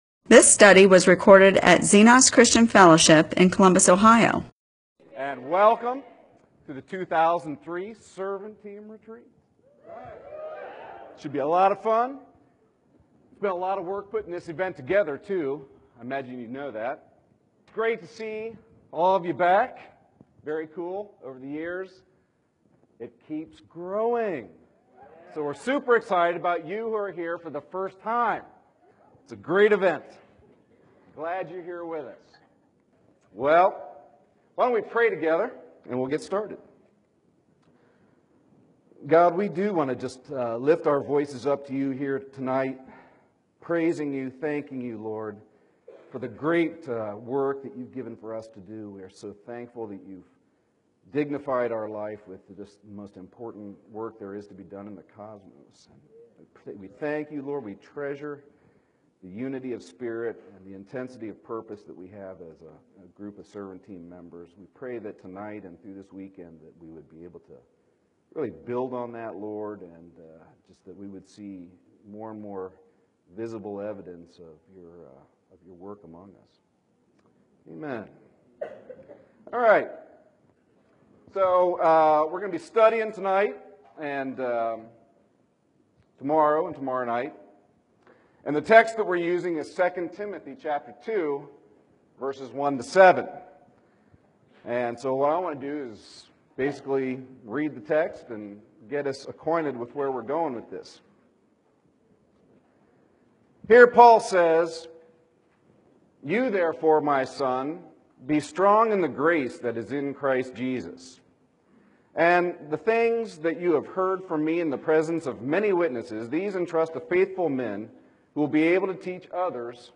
MP4/M4A audio recording of a Bible teaching/sermon/presentation about .